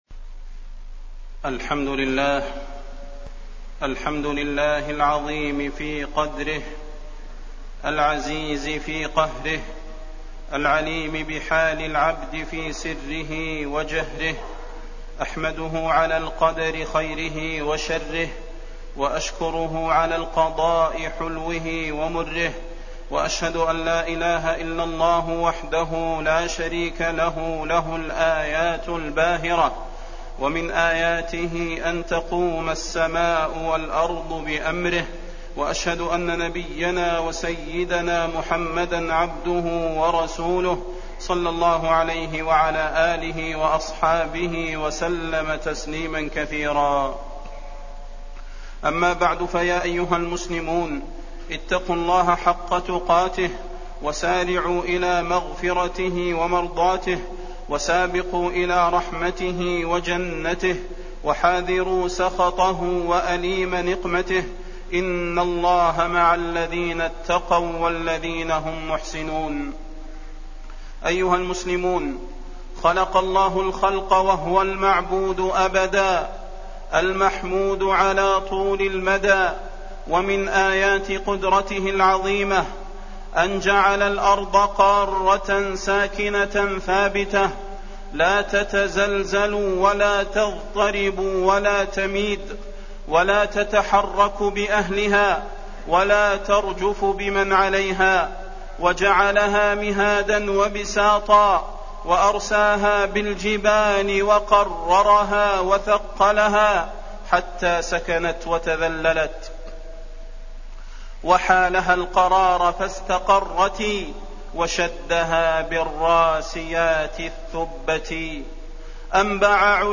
فضيلة الشيخ د. صلاح بن محمد البدير
تاريخ النشر ٢٧ جمادى الأولى ١٤٣٠ هـ المكان: المسجد النبوي الشيخ: فضيلة الشيخ د. صلاح بن محمد البدير فضيلة الشيخ د. صلاح بن محمد البدير الزلازل